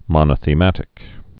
(mŏnə-thē-mătĭk)